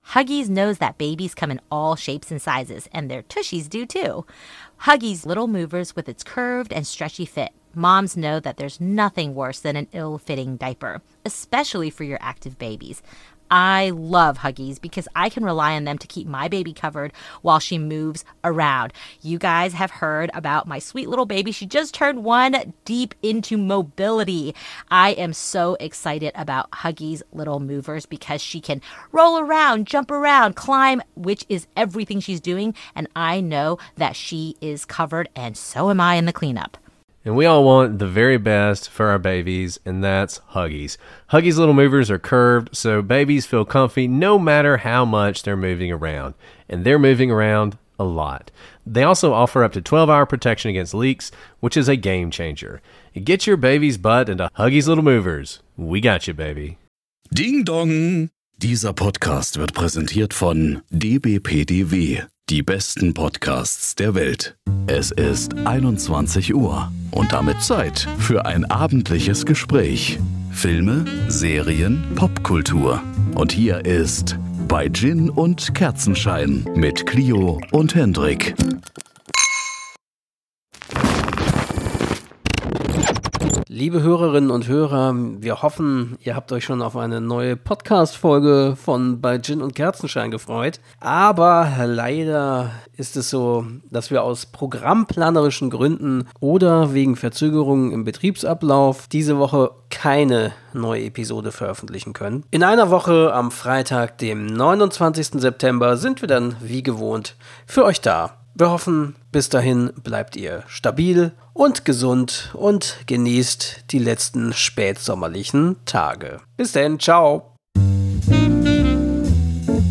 Musik (Intro/Outro): Calm Romantic Restaurant Paris Cocktail Party
Night Club Jazz Music-15 Sec Outro by eitanepsteinmusic via